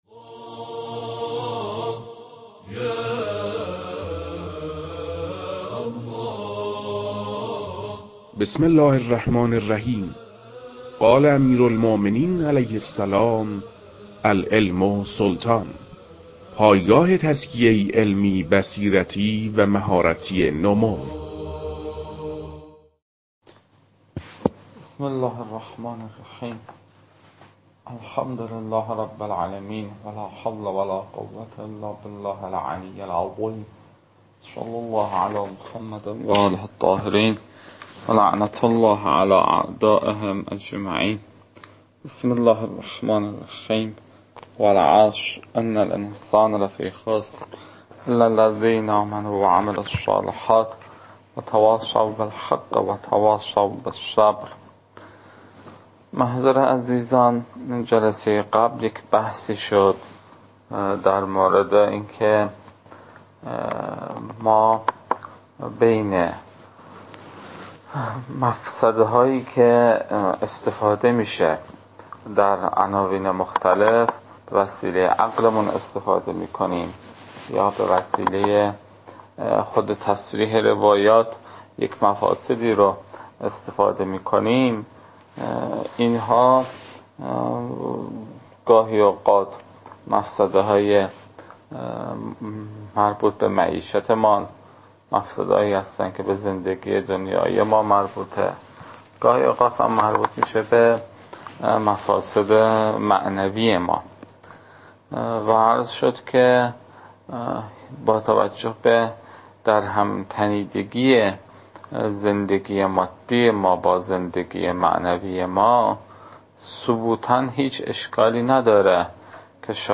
کارگاه ادله ثبوت کراهت و موارد ارتفاع آن در صورت دوم(4)